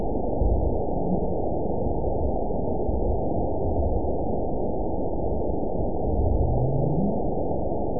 event 917115 date 03/20/23 time 21:31:28 GMT (2 years, 1 month ago) score 9.56 location TSS-AB01 detected by nrw target species NRW annotations +NRW Spectrogram: Frequency (kHz) vs. Time (s) audio not available .wav